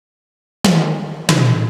Techno / Drum / TOM002_TEKNO_140_X_SC2.wav